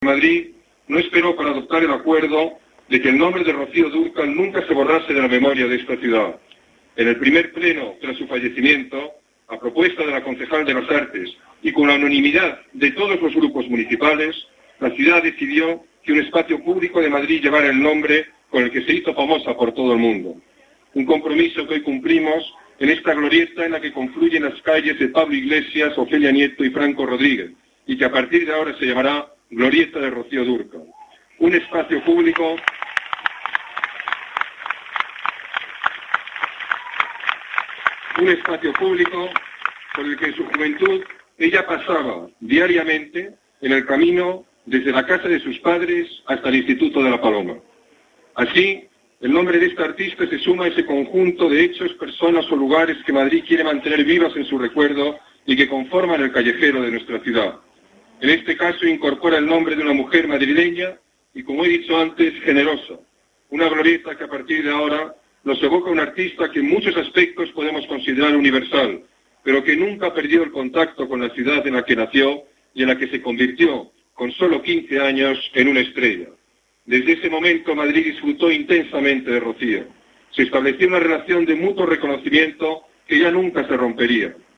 Nueva ventana:El alcalde ha recordado la figura de Rocío Dúrcal en la inauguración de la glorieta que llevará su nombre